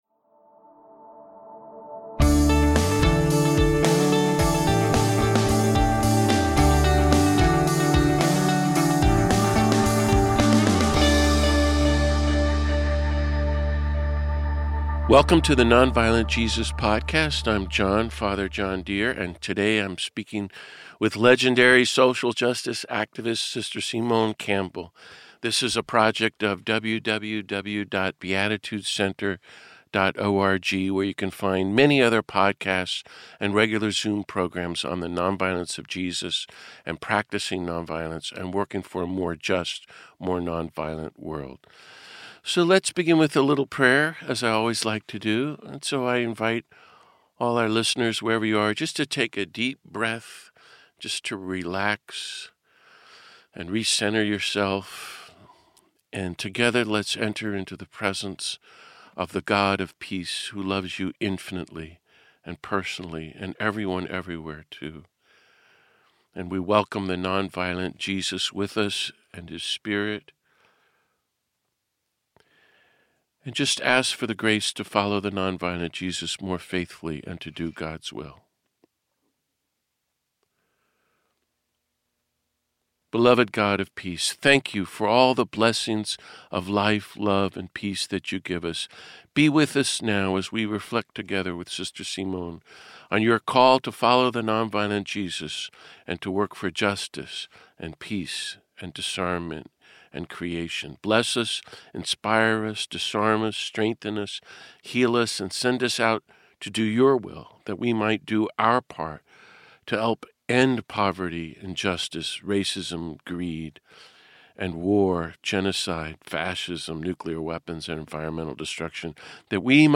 This week I speak with Sr. Simone Campbell, one of the strongest voices, organizers, and leaders for social and economic justice in the U.S. A Sister of Social Service, Sr. Simone is a religious leader, attorney, author, and recipient of the 2022 Presidential Medal of Freedom.